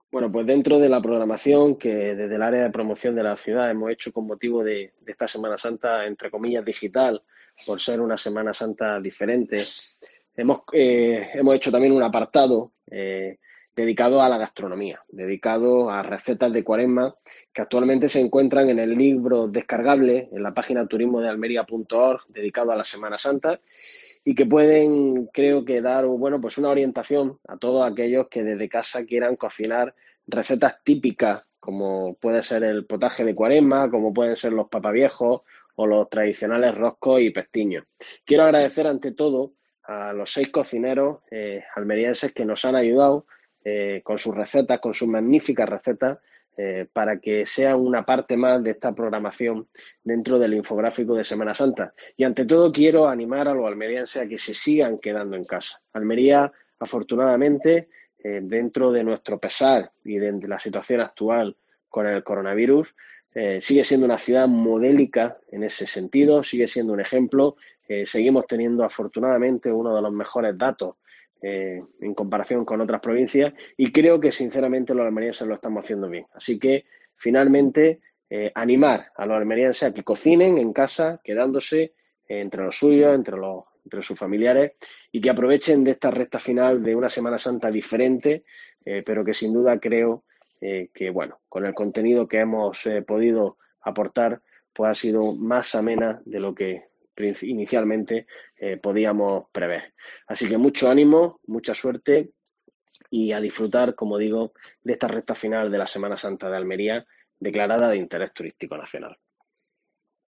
El edil de Promoción de la ciudad, Carlos Sánchez, informa sobre el libro descargable de recetas